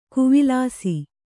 ♪ kuvilāsi